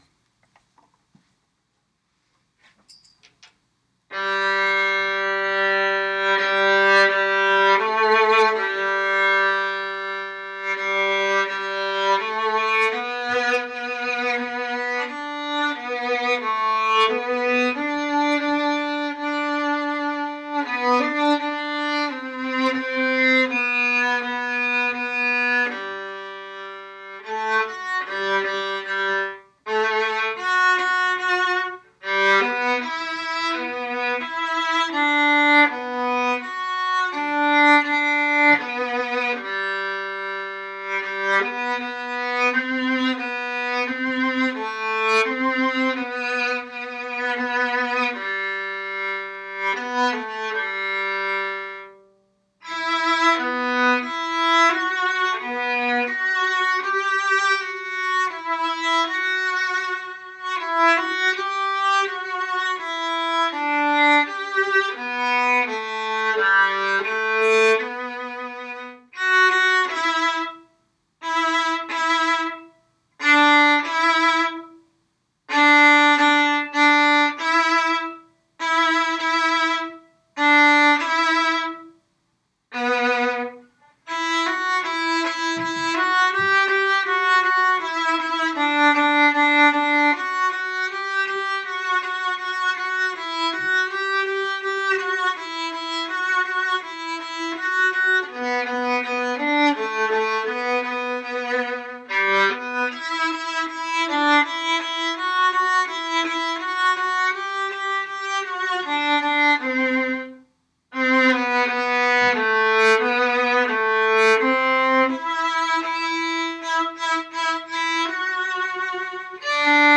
POC viola.m4a